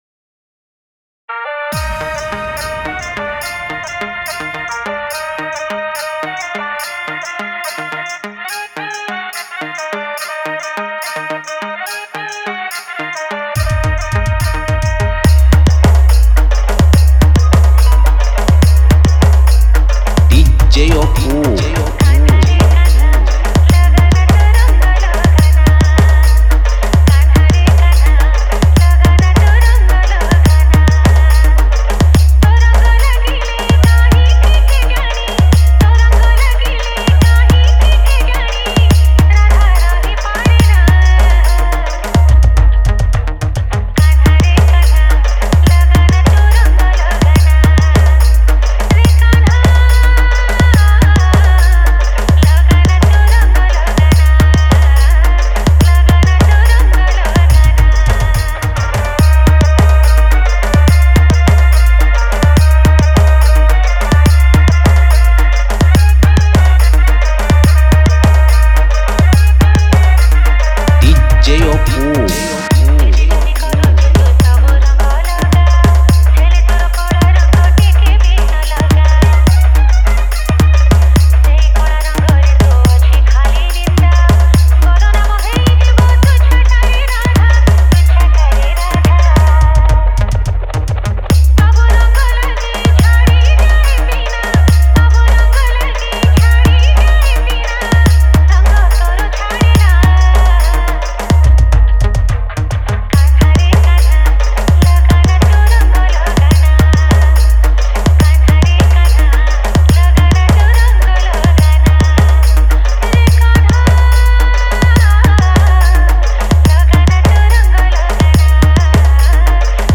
Holi Spl Dance Mix 2021